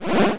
undo.wav